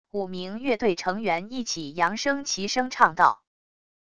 五名乐队成员一起扬声齐声唱到wav音频